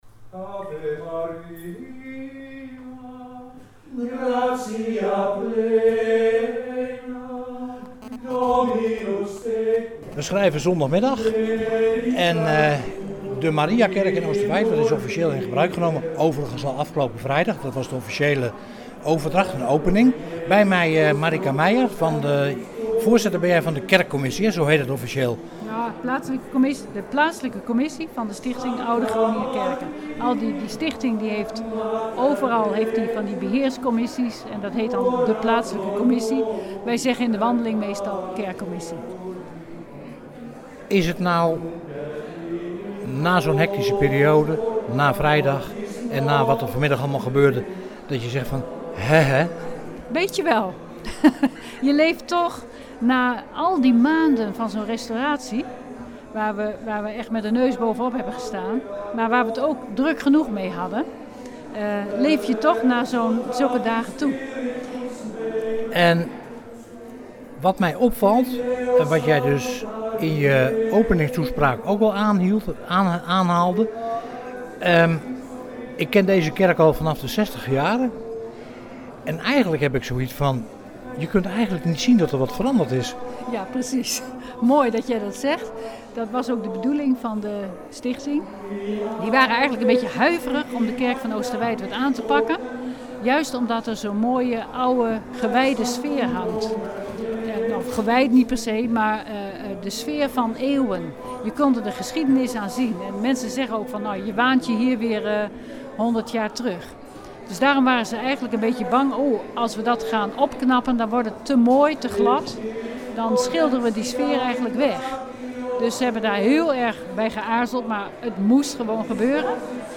Een opname van het interview